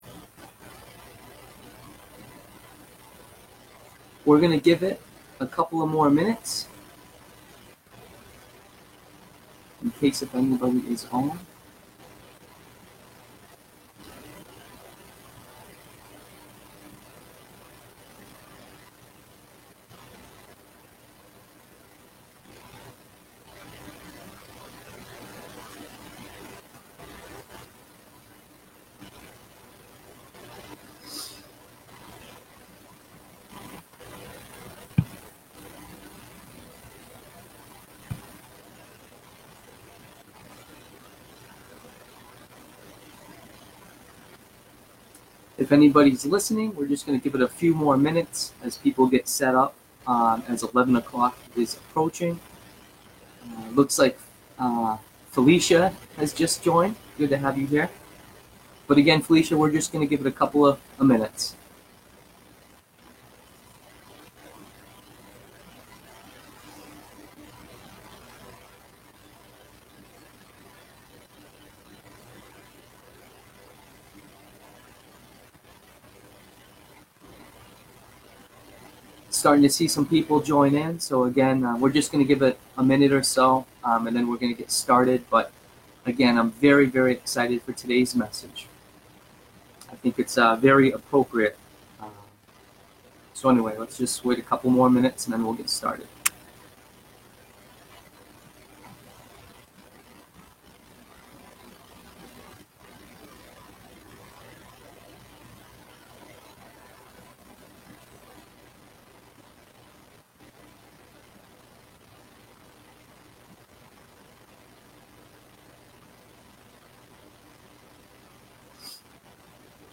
Genre Sermon or written equivalent